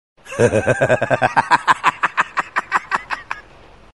Joker Laugh Meme Effect sound effects free download